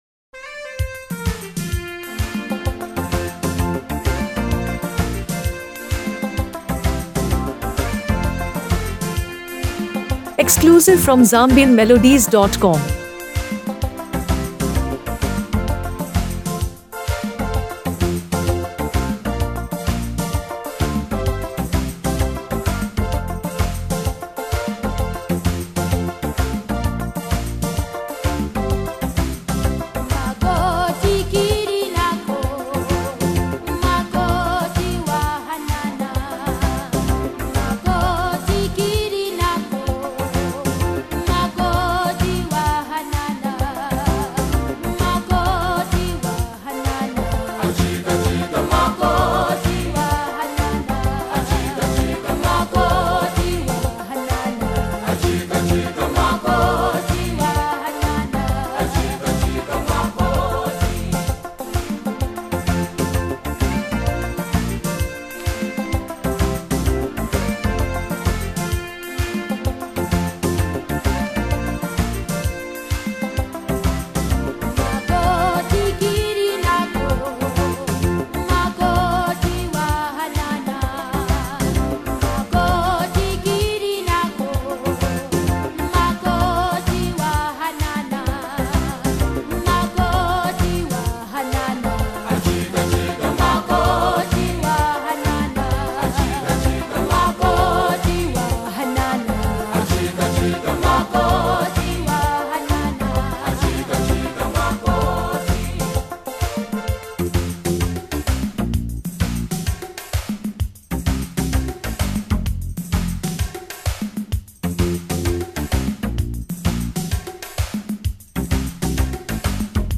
A Soulful Celebration of Love and Tradition
blending soulful harmonies with rich cultural rhythms.
a celebrated Afro-soul group